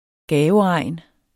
gaveregn substantiv, fælleskøn Bøjning -en Udtale [ ˈgæːvəˌʁɑjˀn ] Betydninger 1. stort antal gaver Hvis man .. skal konfirmeres, kan man se frem til en gaveregn til en samlet værdi af ca. 30.000 kr.